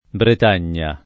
bret#n’n’a] (antiq. Brettagna [brett#n’n’a]) top. f. — es.: la Borgogna, la Brettagna, la Guascogna e la Normandia [la borg1n’n’a, la brett#n’n’a, la gUaSk1n’n’a e lla normand&a] (Machiavelli); Bretagna che fu poi detta Inghilterra [bret#n’n’a ke ffu pp0i d%tta ijgilt$rra] (Ariosto) — tuttora brettagna come nome di fiore (s. f.) — fr. Bretagne [brët#n’] — cfr. Bertagna; Gran Bretagna